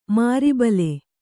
♪ māri bale